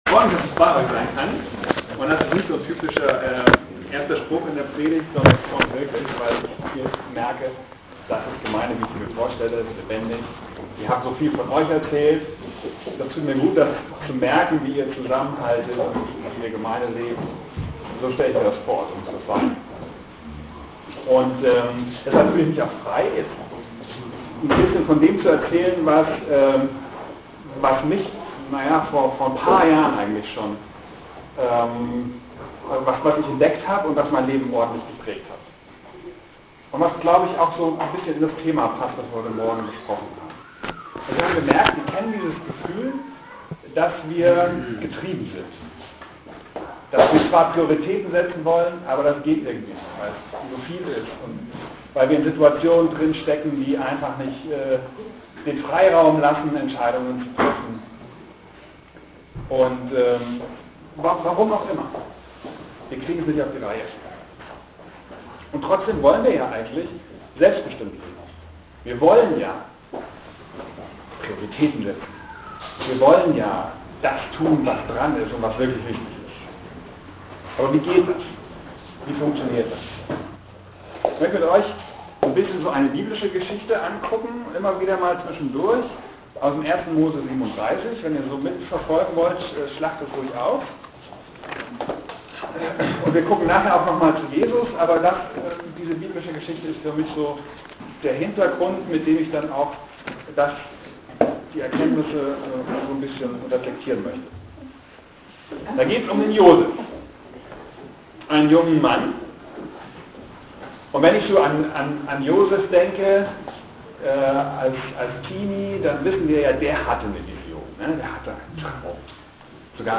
Predigten zum download